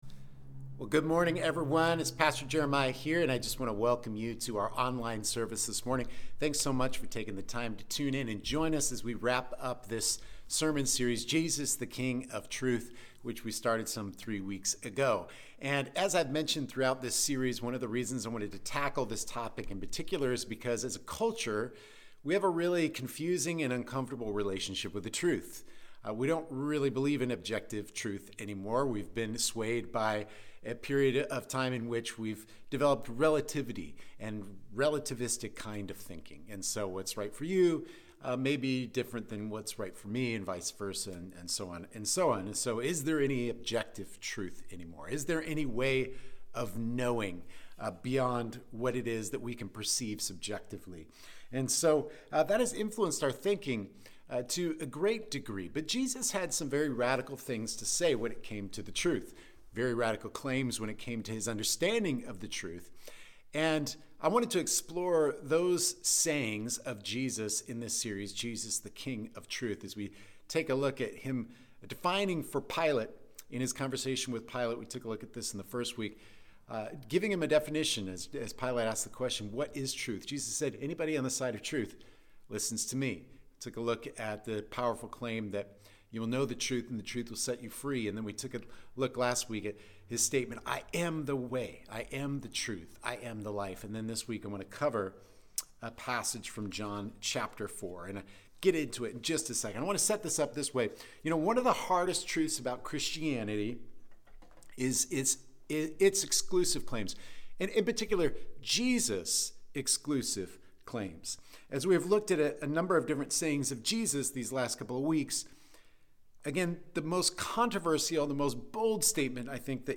Sunday-Audio-2.5.23.mp3